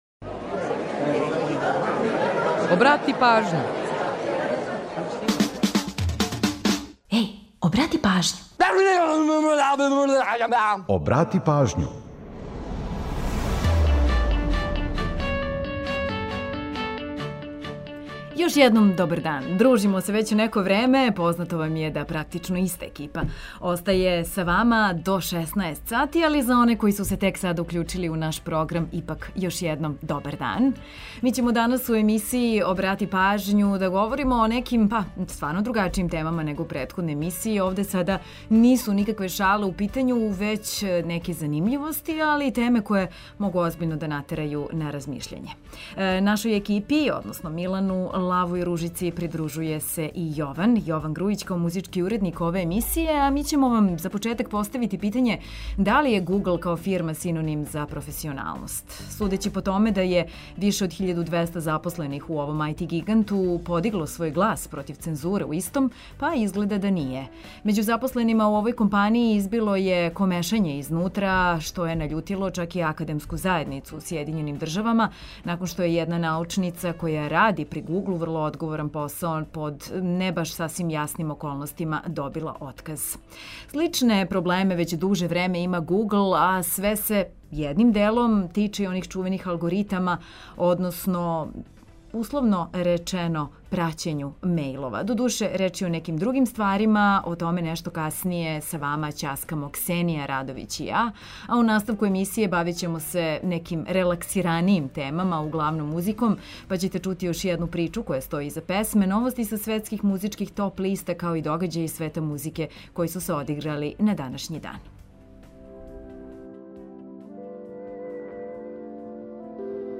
У наставку емисије бавимо се, углавном, музиком. Чућете још једну причу која стоји иза песме, новости са светских топ листа као и догађаје из света музике који су се одиграли на данашњи дан.